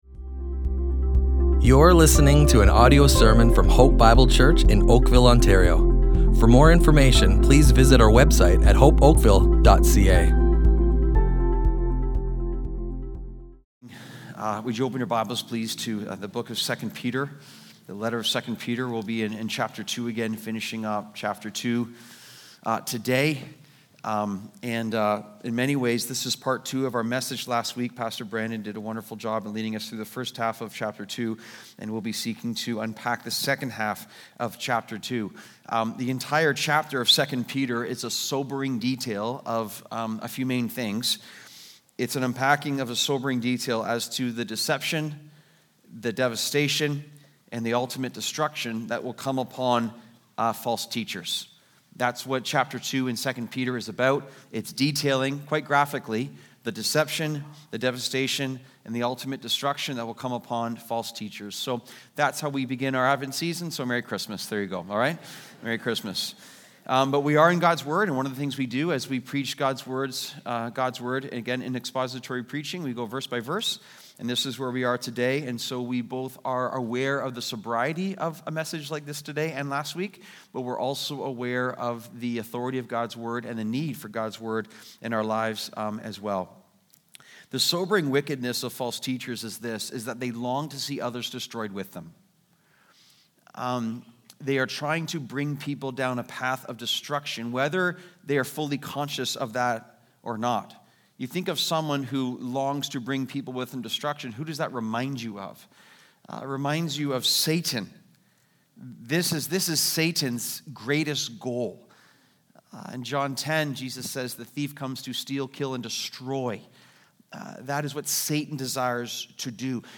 Hope Bible Church Oakville Audio Sermons